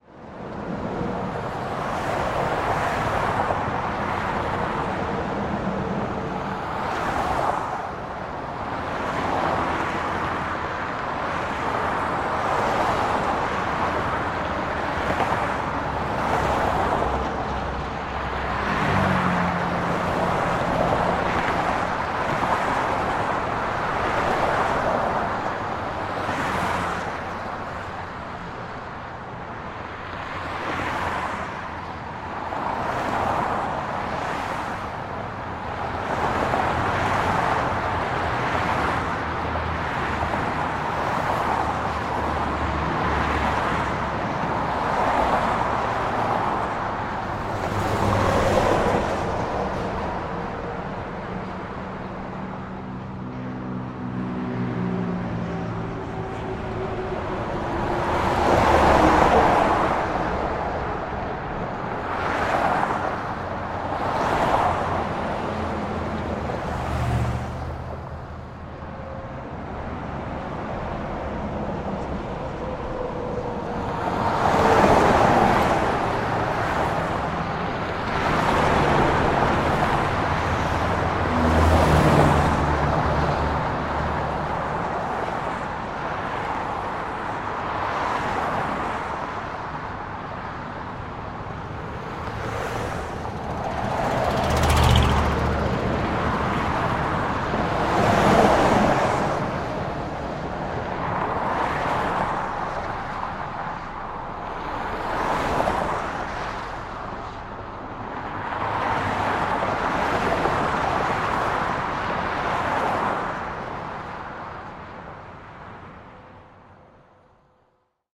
Звуки шоссе, дорог
Шум ветра и машин на шоссе